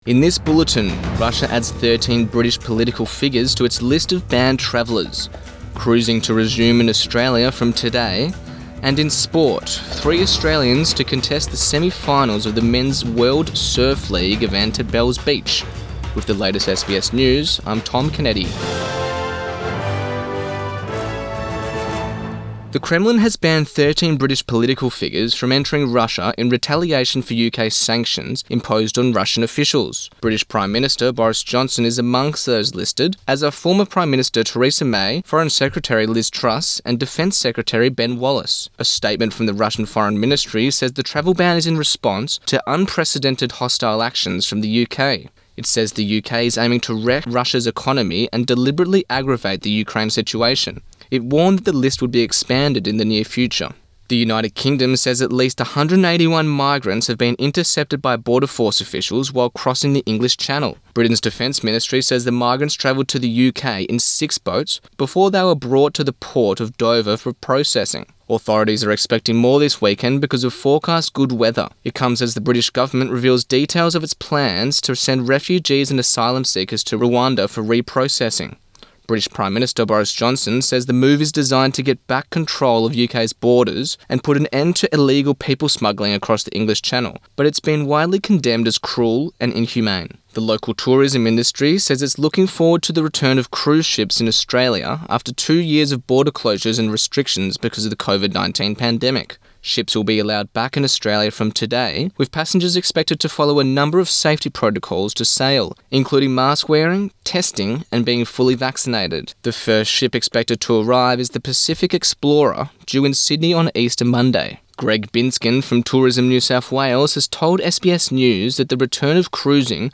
AM bulletin 17 April 2022